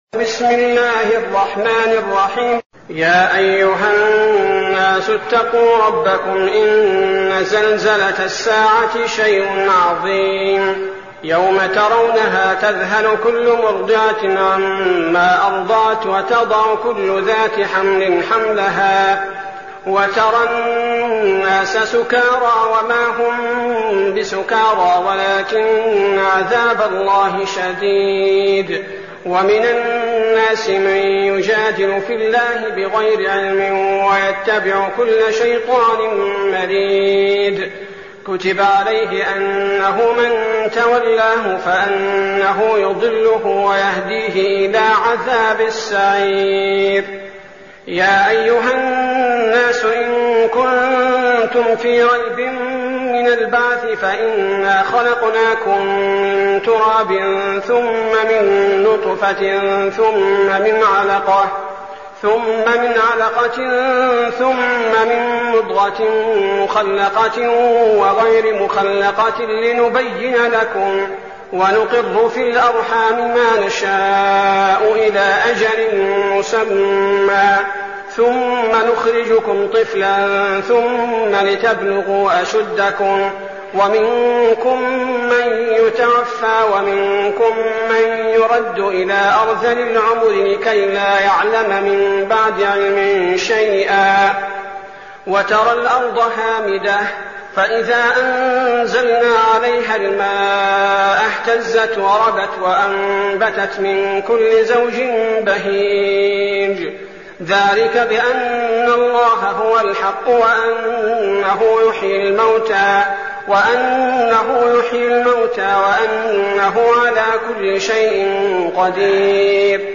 المكان: المسجد النبوي الشيخ: فضيلة الشيخ عبدالباري الثبيتي فضيلة الشيخ عبدالباري الثبيتي الحج